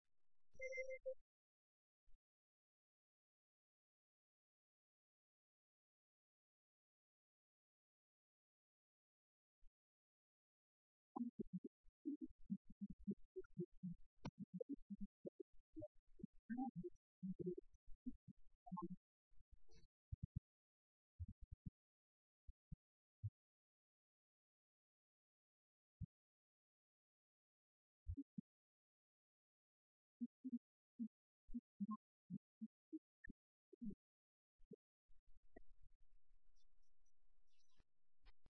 позивні 2012 р.